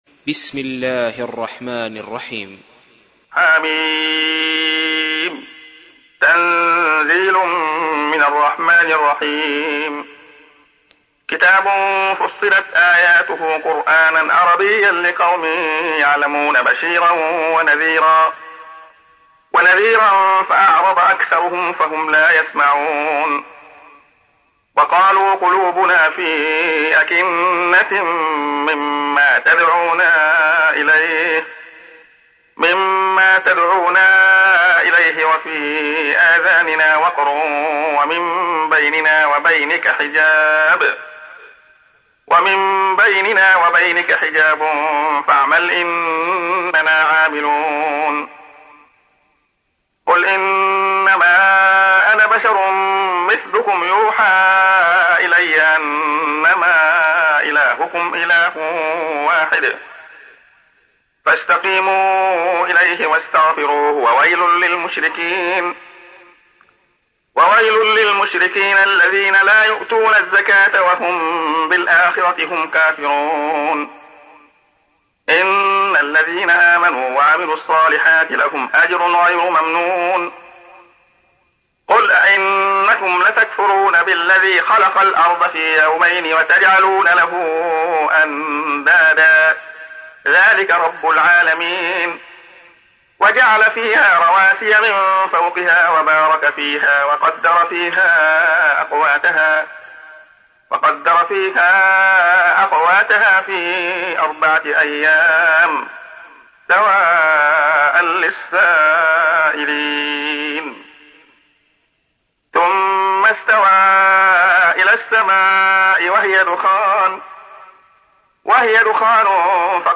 تحميل : 41. سورة فصلت / القارئ عبد الله خياط / القرآن الكريم / موقع يا حسين